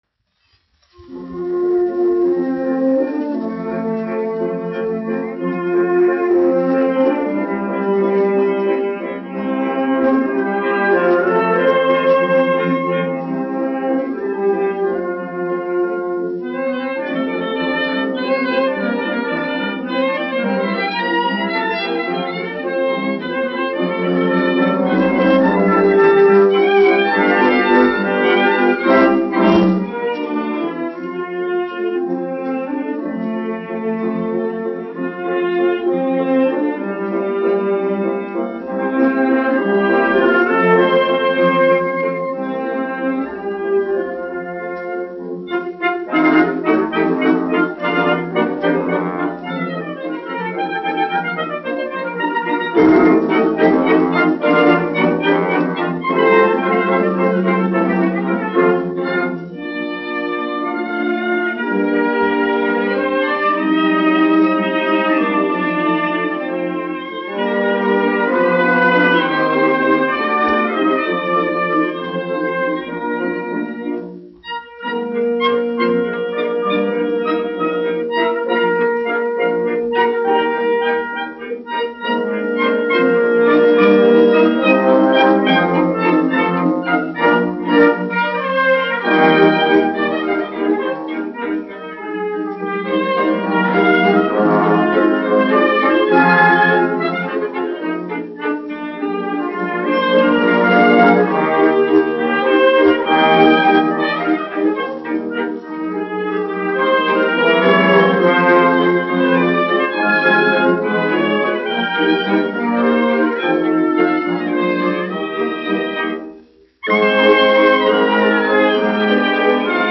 Rīgas Radiofona kamerorķestris, izpildītājs
1 skpl. : analogs, 78 apgr/min, mono ; 25 cm
Valši
Orķestra mūzika
Skaņuplate